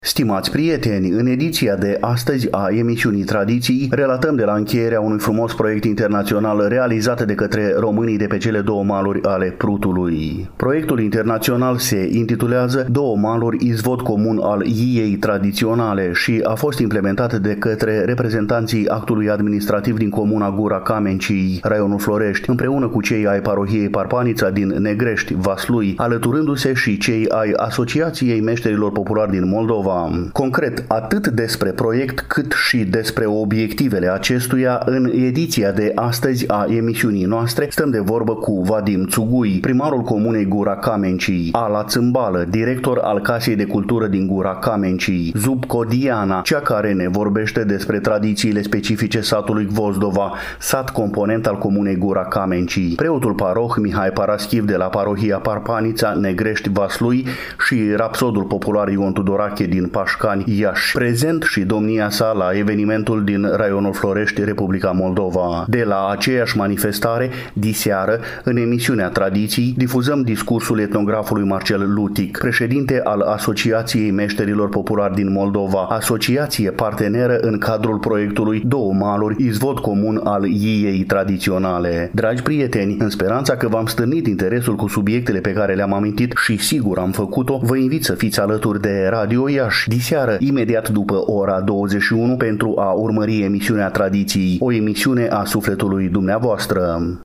Stimați prieteni, în ediția de astăzi a emisiunii Tradiții, relatăm de la încheierea unui frumos proiect internațional realizat de către românii de pe cele două maluri ale Prutului.